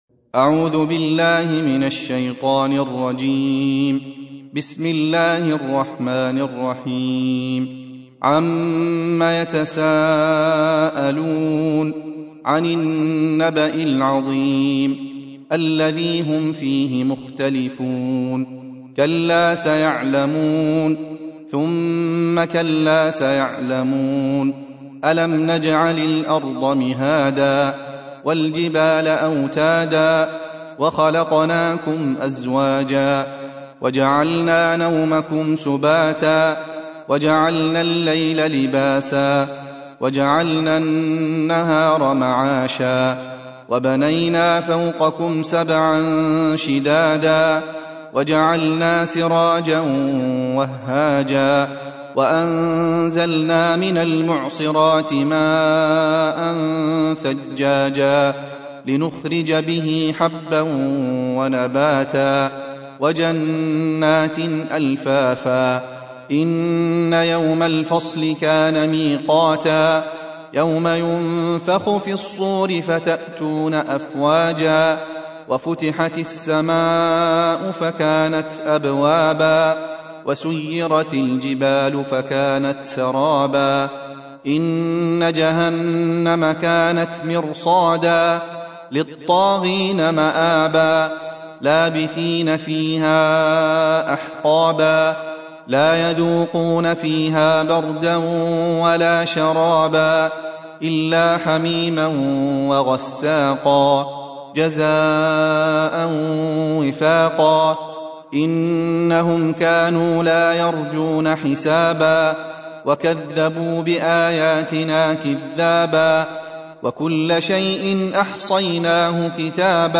Surah Repeating تكرار السورة Download Surah حمّل السورة Reciting Murattalah Audio for 78. Surah An-Naba' سورة النبأ N.B *Surah Includes Al-Basmalah Reciters Sequents تتابع التلاوات Reciters Repeats تكرار التلاوات